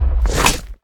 Minecraft Version Minecraft Version snapshot Latest Release | Latest Snapshot snapshot / assets / minecraft / sounds / mob / evocation_illager / fangs.ogg Compare With Compare With Latest Release | Latest Snapshot
fangs.ogg